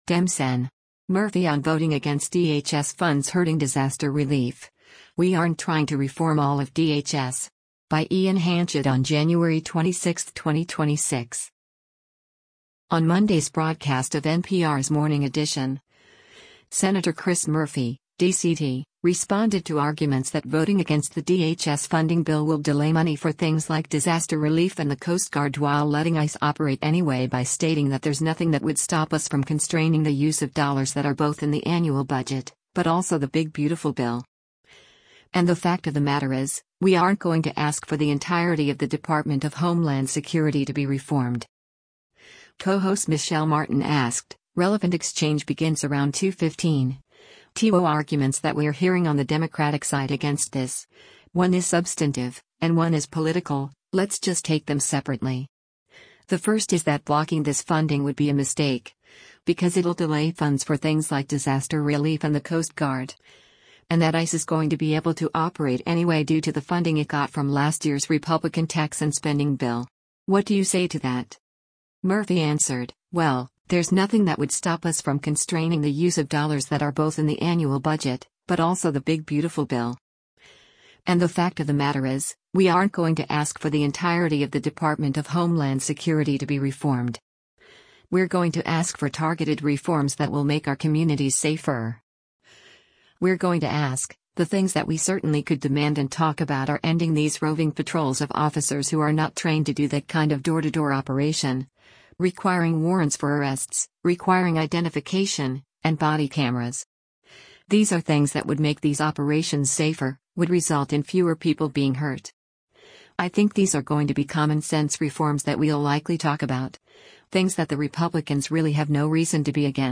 On Monday’s broadcast of NPR’s “Morning Edition,” Sen. Chris Murphy (D-CT) responded to arguments that voting against the DHS funding bill will delay money for things like disaster relief and the Coast Guard while letting ICE operate anyway by stating that “there’s nothing that would stop us from constraining the use of dollars that are both in the annual budget, but also the Big Beautiful Bill. And the fact of the matter is, we aren’t going to ask for the entirety of the Department of Homeland Security to be reformed.”